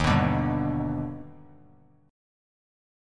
D#+M7 chord